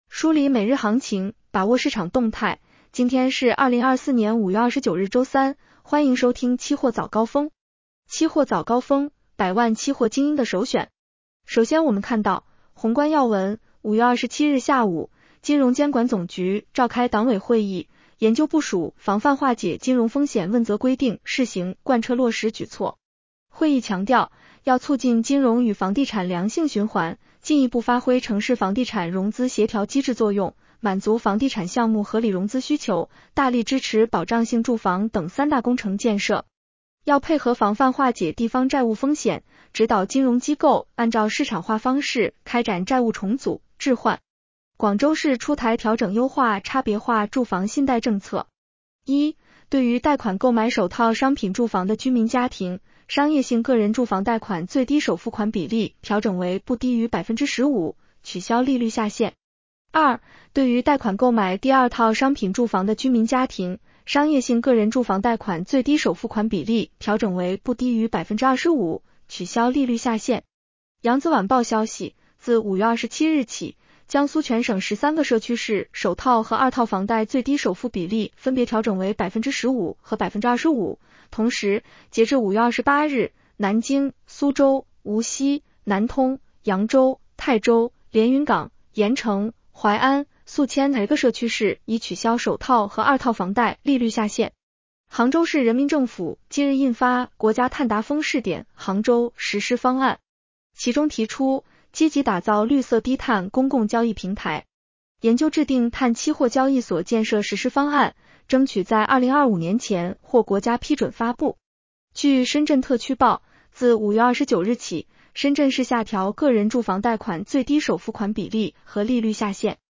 期货早高峰-音频版 女声普通话版 下载mp3 宏观要闻 1. 5月27日下午，金融监管总局召开党委会议，研究部署《防范化解金融风险问责规定（试行）》贯彻落实举措。